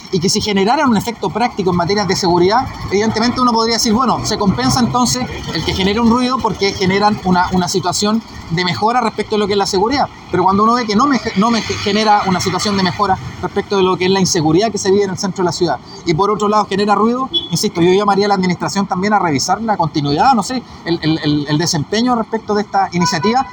El concejal Boris Negrete sabe de esos reclamos, y si bien los parlantes cumplen con la normativa de decibeles, lo cierto es que en el día apenas se escucha y los fines de semana son molestos y como su efectividad es muy escasa él cree que se debe evaluar la continuidad de estos parlantes.